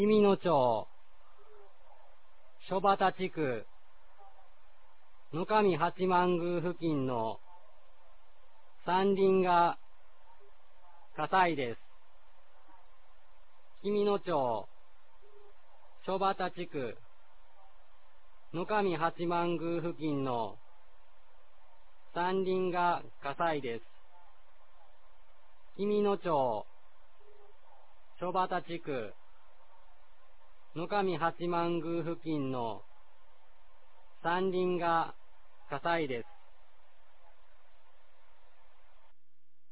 2022年05月10日 22時08分に、紀美野町より全地区へ放送がありました。